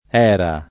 Pontedera; Val d’Era — così anche un grande fiume in Fr., nominato da Dante (e dal Petrarca), identificato dai più con la Loira, da altri con la Saona — per il paese in Lomb., pn. loc. con E- chiusa — per il cogn., cfr.